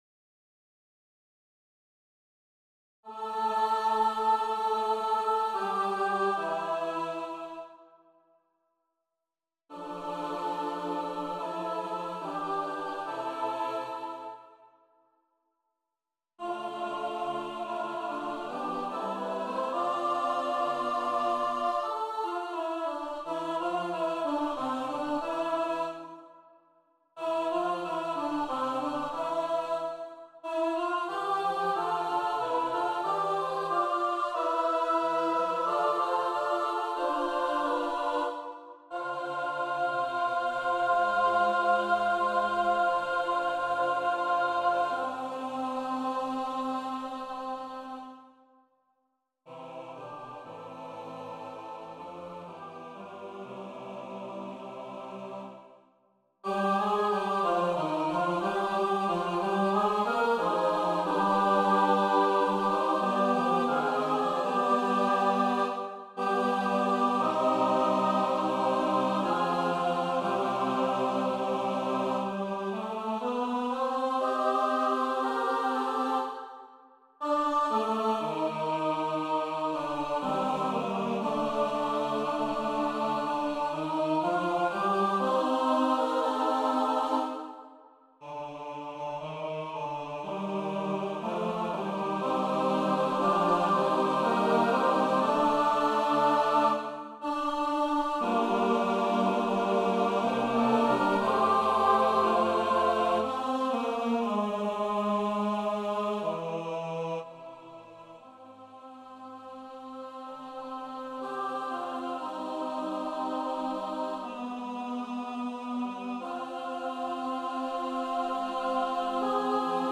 Synthesized Performance (Choir) *#827948